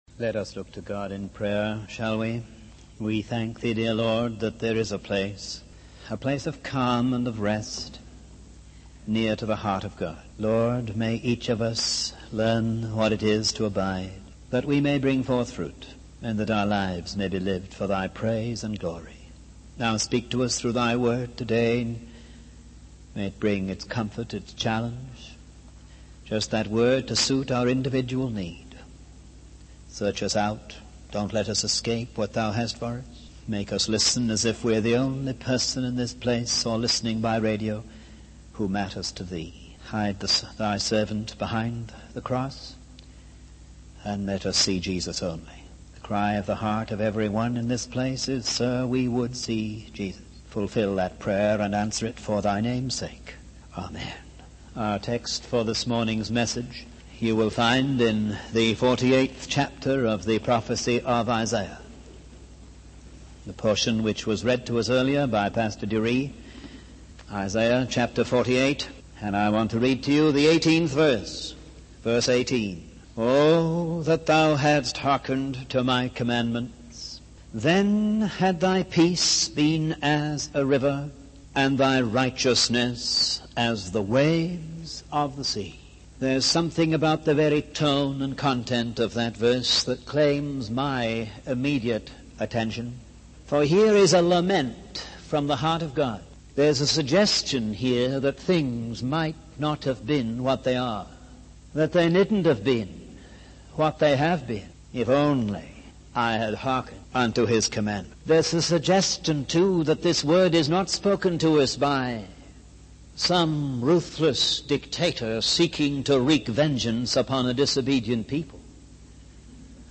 In this sermon, the preacher emphasizes the simplicity of the road to revival, which is obedience to God's commandments. He highlights the importance of not only hearing God's word but also putting it into practice.